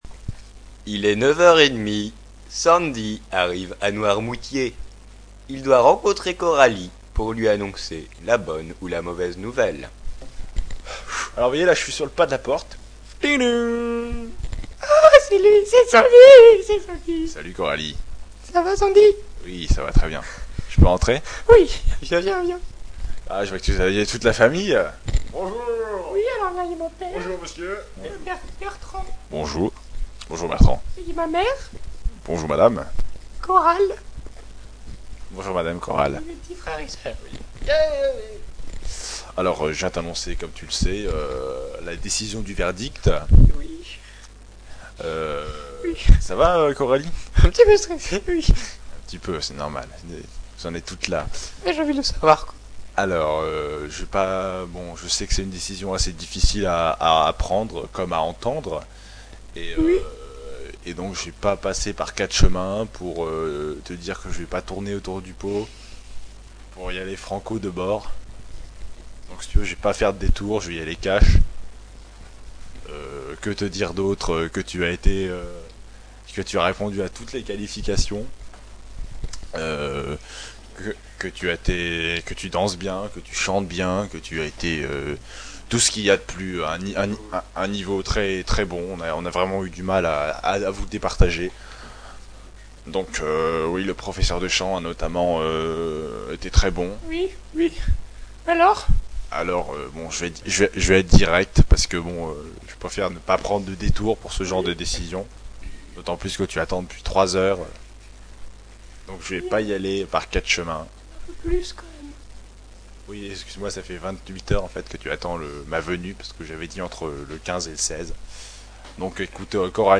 Genre: Rock Play